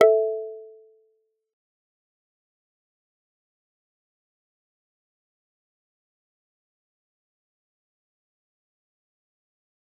G_Kalimba-A4-f.wav